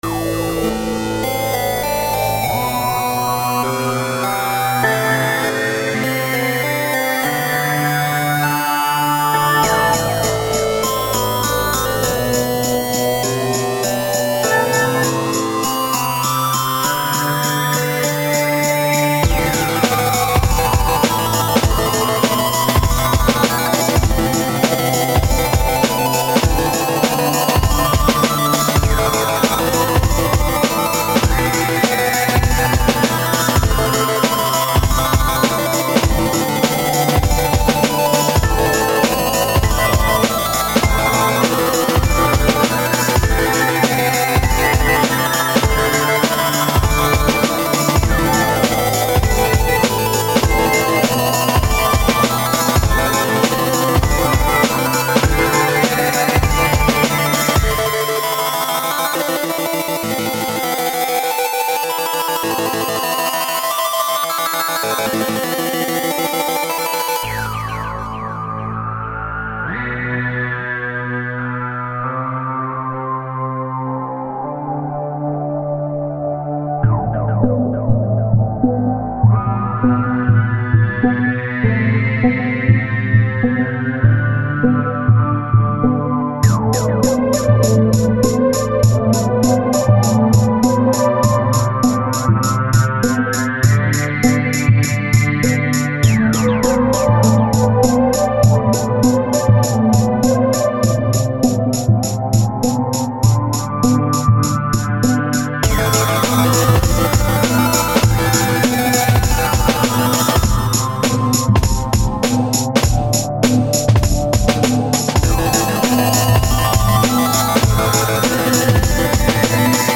Нечто экспериментальное, но крутое)